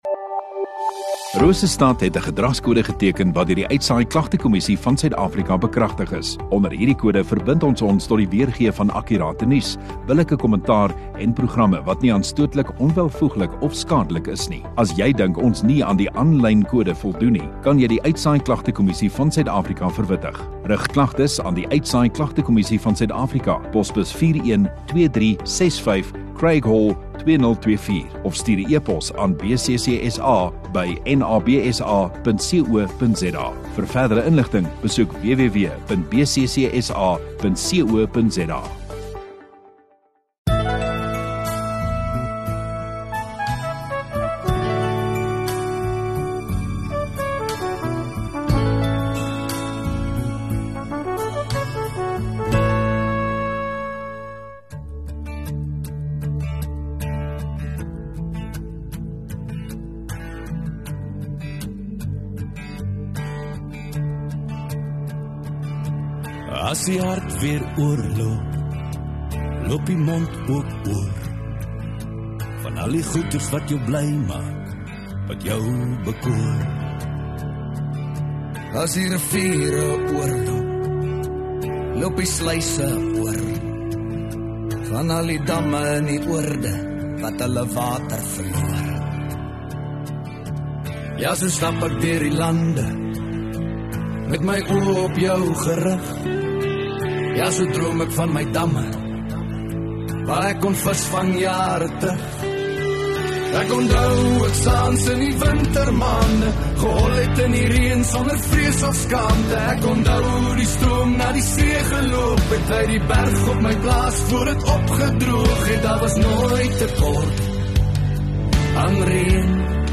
29 Oct Sondagaand Erediens